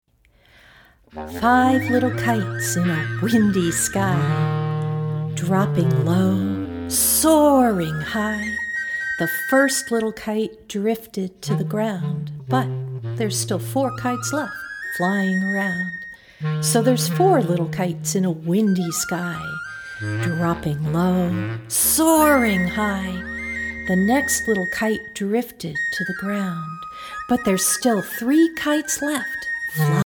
A wonderfully engaging fingerplay!
Listen to a sample of this song.